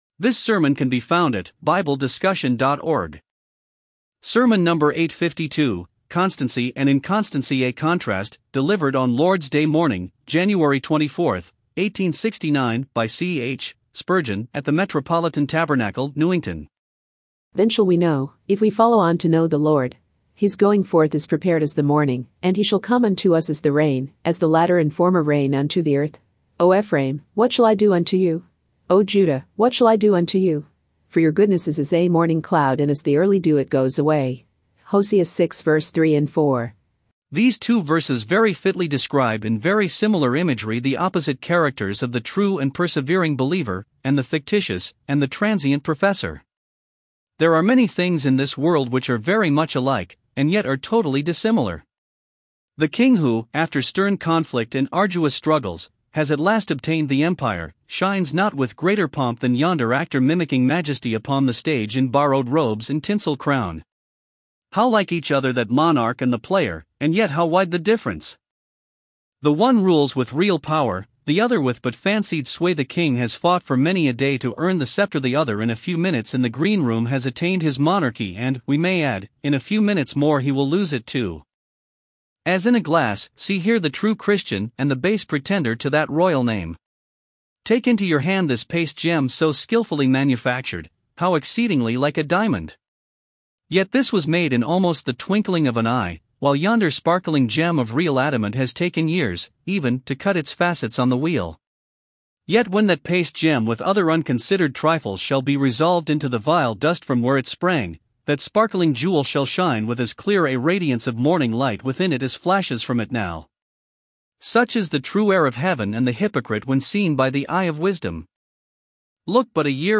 Sermon #856 – THE IMPORTUNATE WIDOW